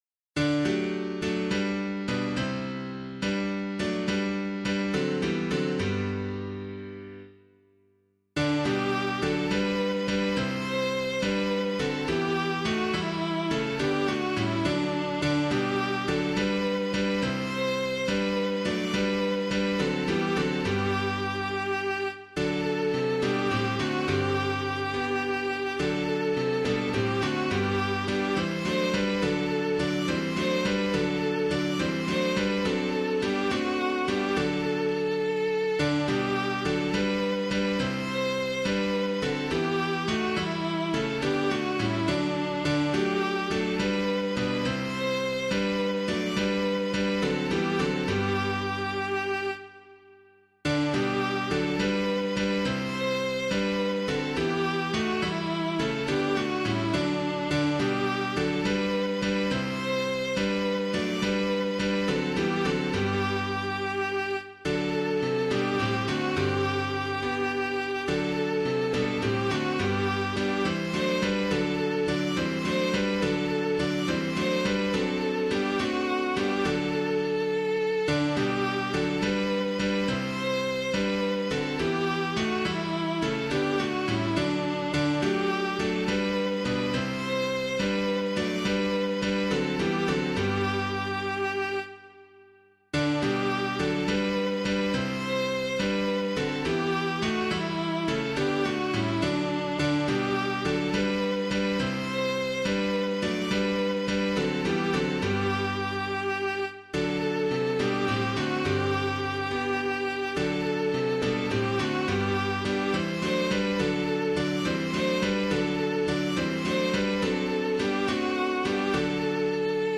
Hymn suitable for Catholic liturgy
O Come Divine Messiah [Mary of Saint Philip - VENEZ DIVIN MESSIE] - piano.mp3